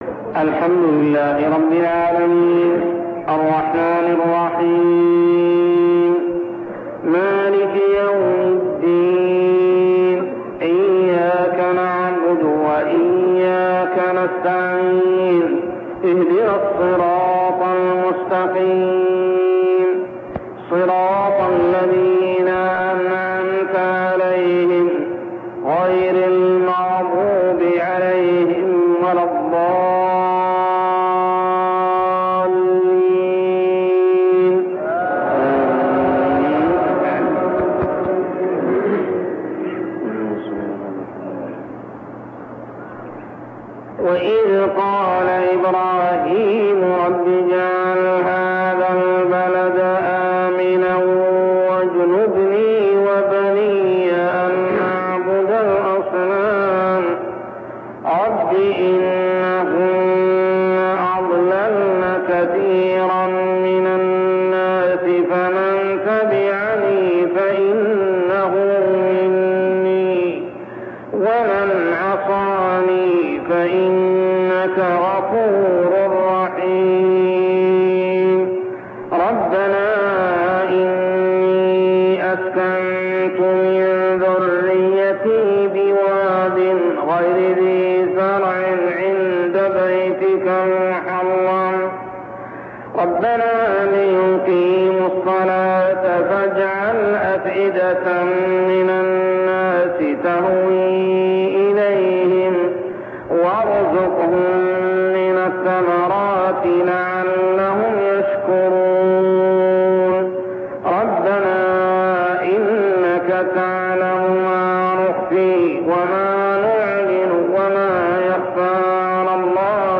تلاوة من صلاة الفجر لخواتيم سورة إبراهيم 35-52 عام 1402هـ | Fajr prayer Surah Ibrahim > 1402 🕋 > الفروض - تلاوات الحرمين